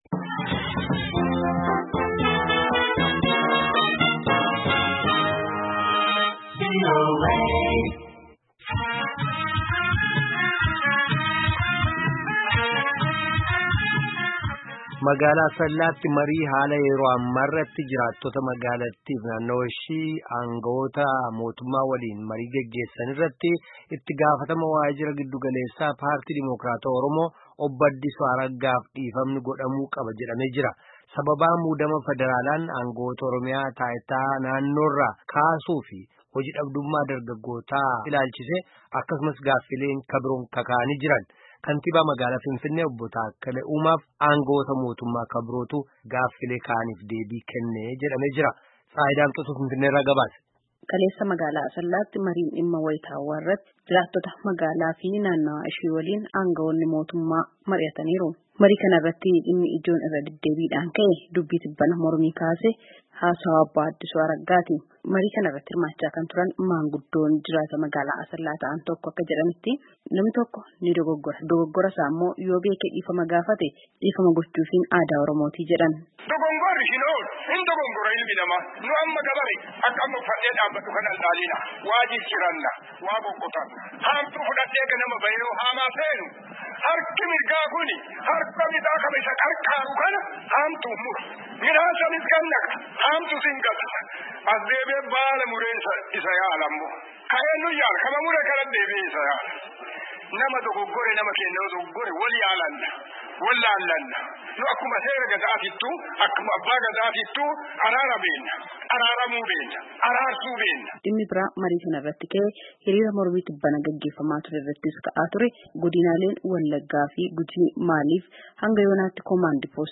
Kantiibaa magalaa Finfinnee obbo Takkala Umaa fi angawotaa motummaa ka biroo tu gaaffiilee ka’aniif deebii kenne. Gabaasaa guutuu caqasaa.